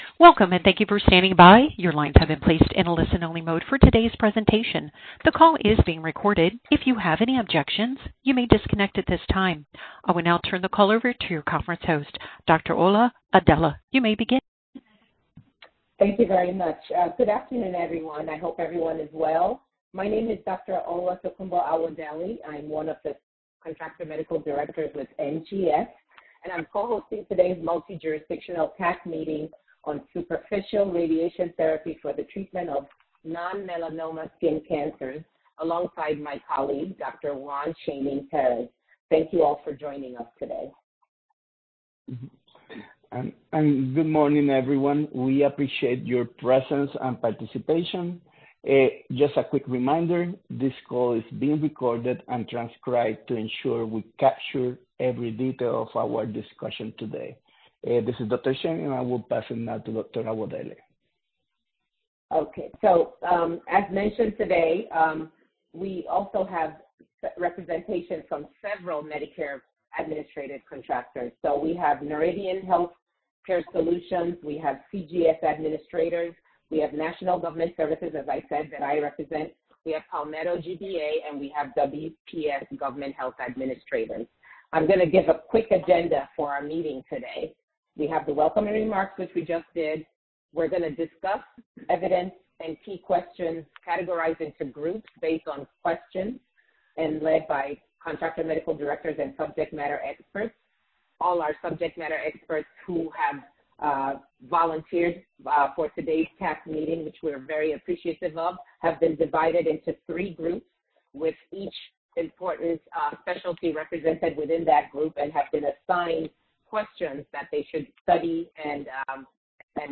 Multijurisdictional Contractor Advisory Committee Meeting